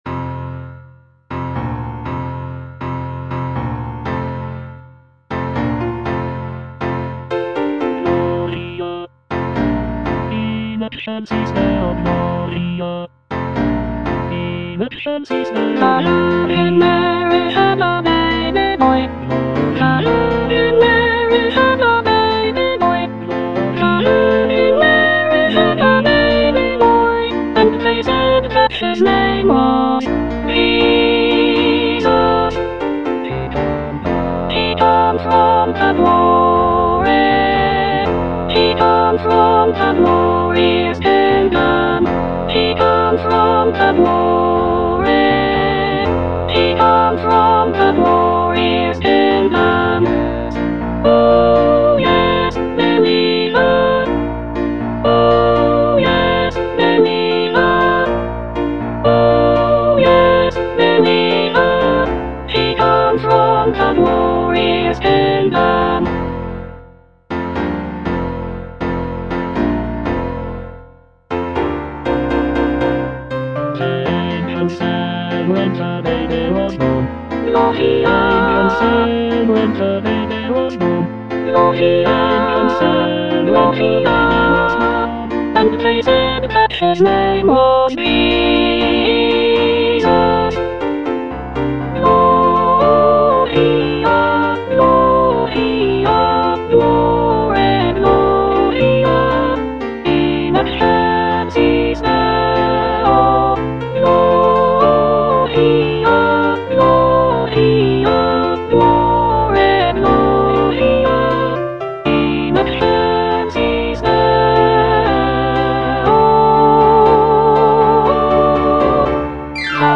Soprano I (Emphasised voice and other voices)
" set to a lively calypso rhythm.